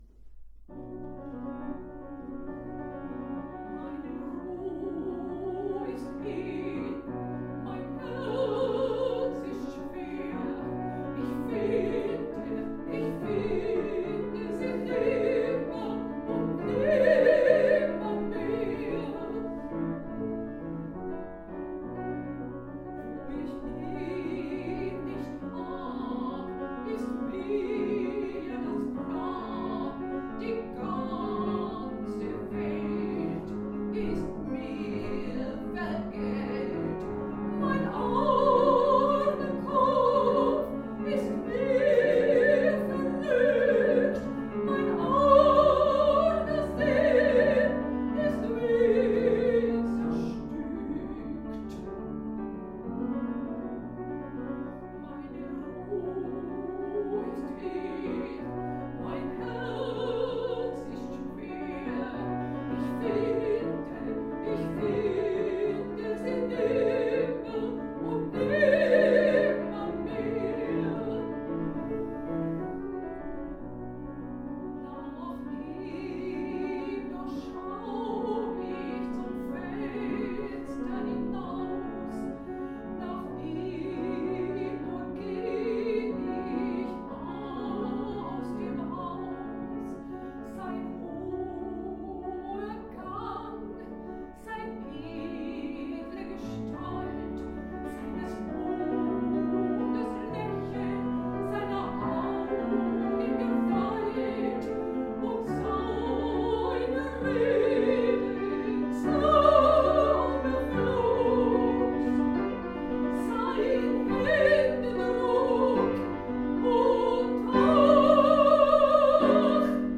am Klavier